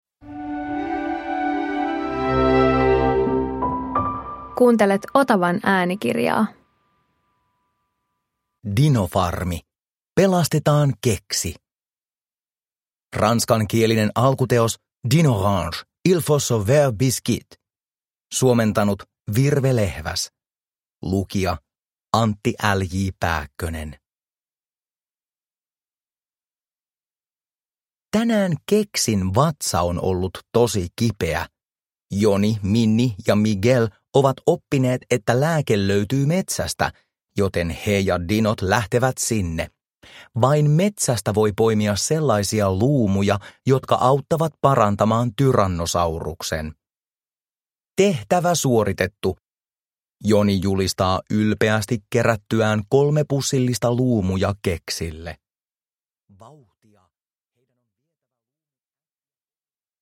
Dinofarmi - Pelastetaan Keksi – Ljudbok – Laddas ner